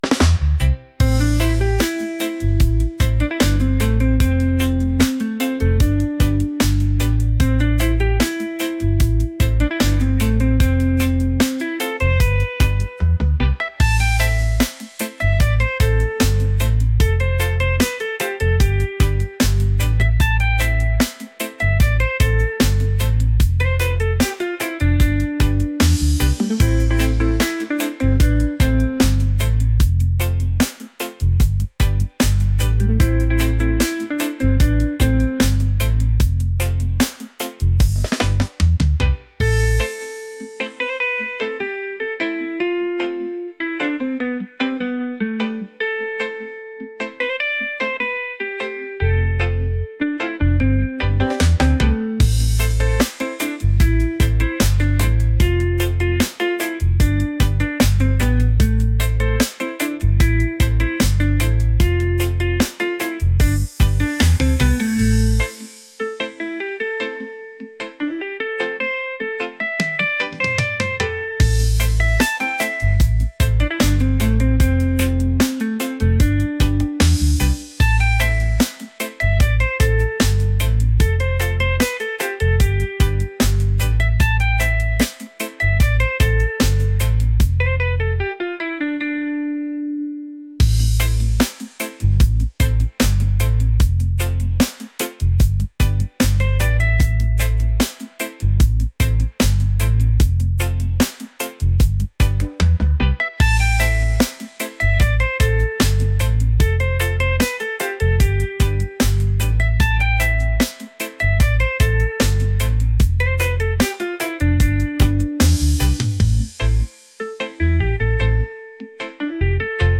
reggae | upbeat | relaxed